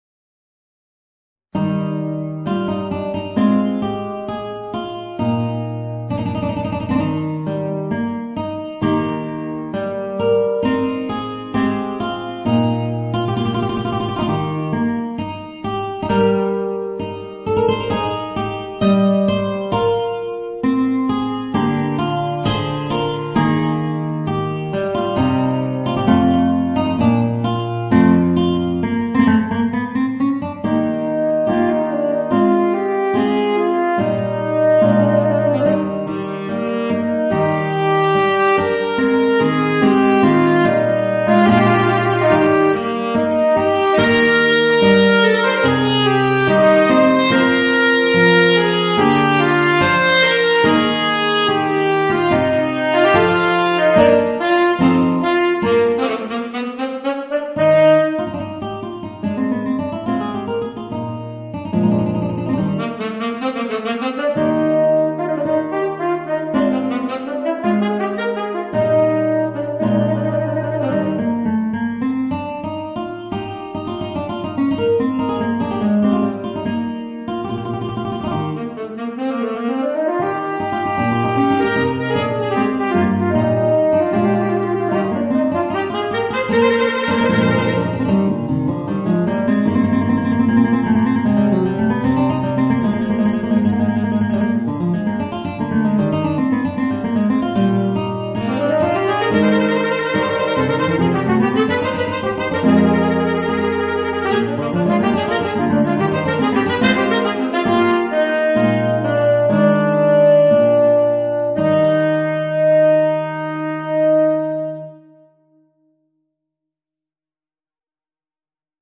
Voicing: Tenor Saxophone and Guitar